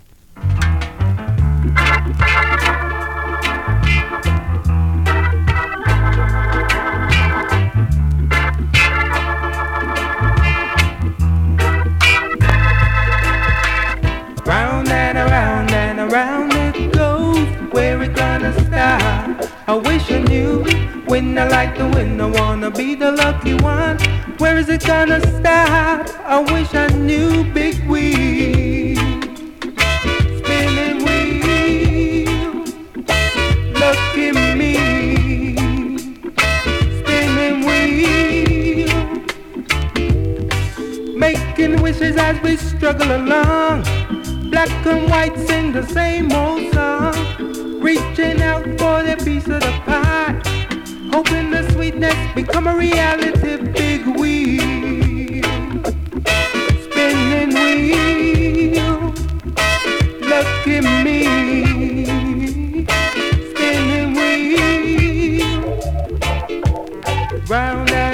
ホーム > 2021 NEW IN!!SKA〜REGGAE!!
スリキズ、ノイズかなり少なめの